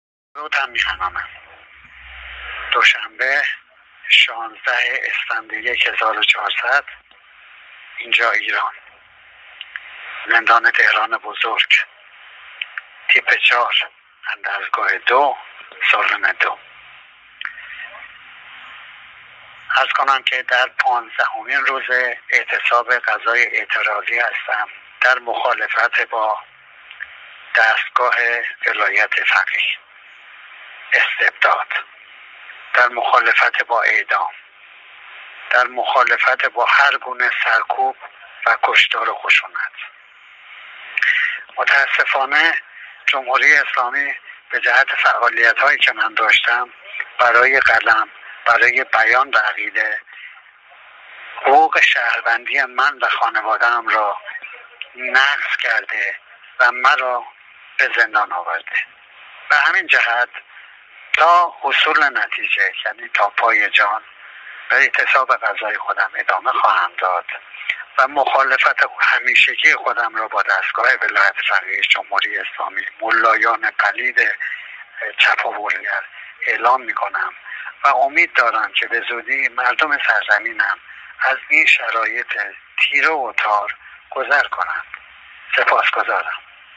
با ارسال فایلی صوتی از زندان تهران بزرگ